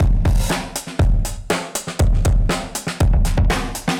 Index of /musicradar/dusty-funk-samples/Beats/120bpm/Alt Sound